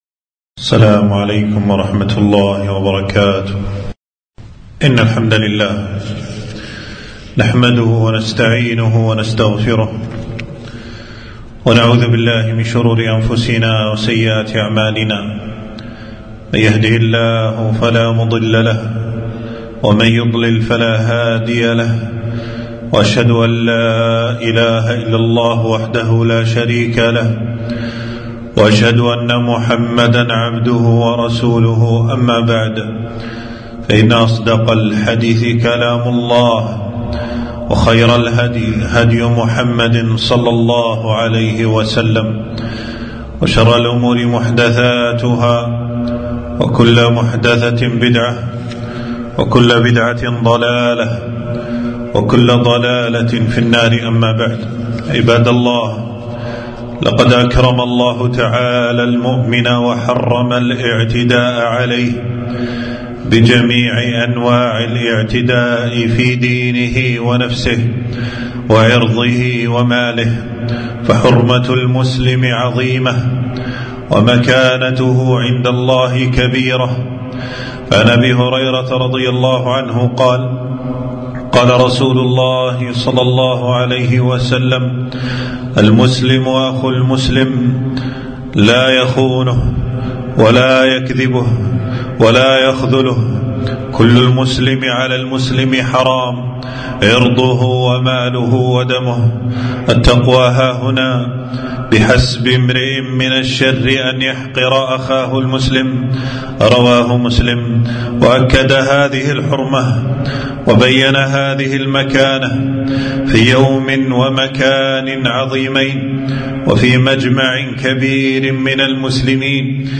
خطبة - من أشراط الساعة كثرة القتل وانتشاره